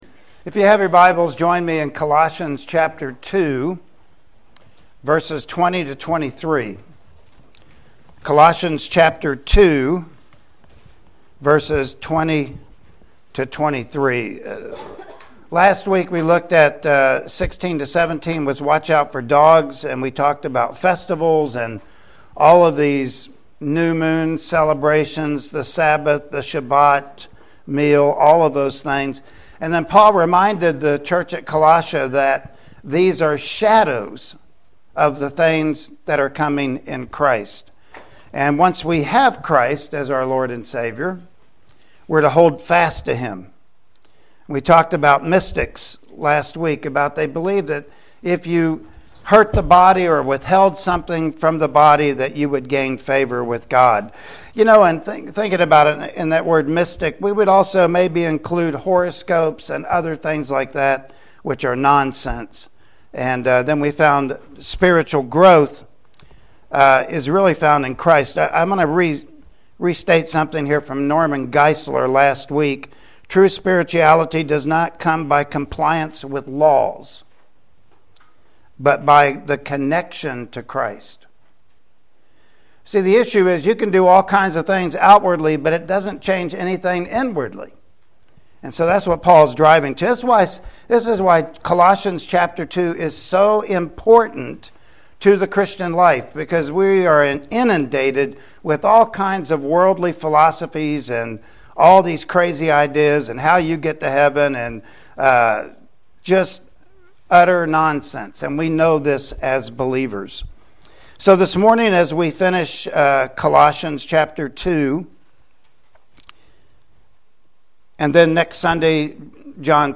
Colossians 2:20-23 Service Type: Sunday Morning Worship Service Topics: Man-Made Religion « Shadows For God So Love…